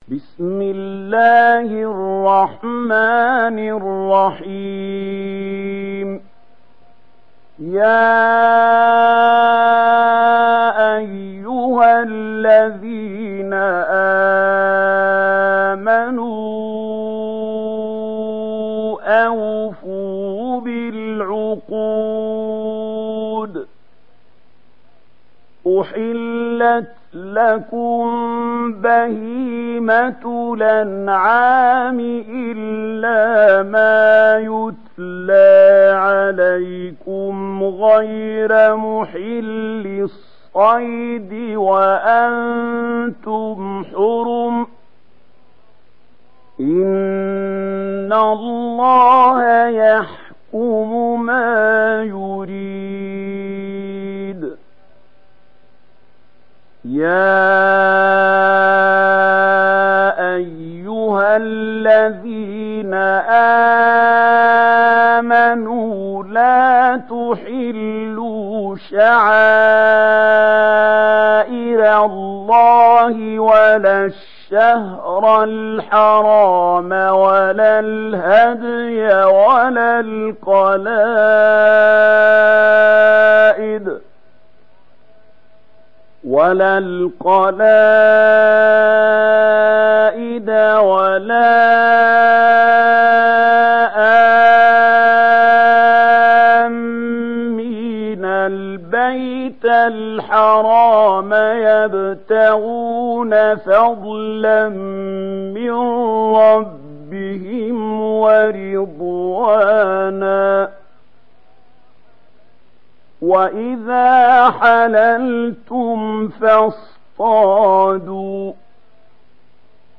دانلود سوره المائده mp3 محمود خليل الحصري روایت ورش از نافع, قرآن را دانلود کنید و گوش کن mp3 ، لینک مستقیم کامل